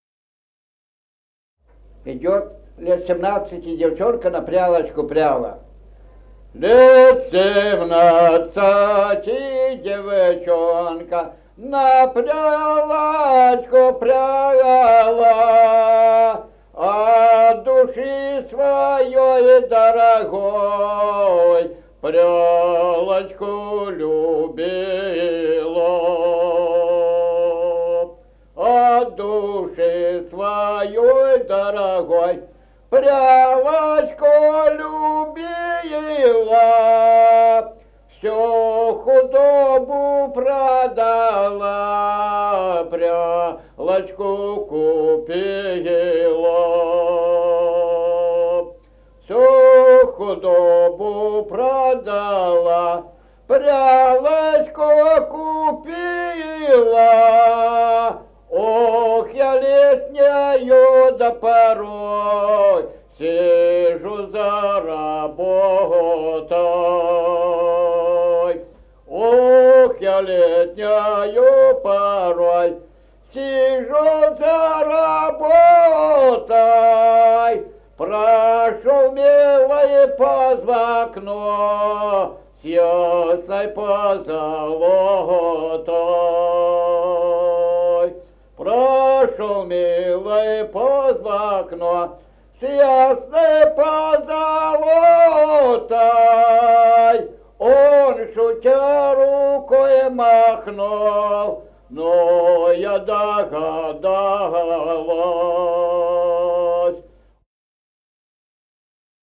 Музыкальный фольклор села Мишковка «Лет семнадцати девчонка», лирическая, репертуар скрипача.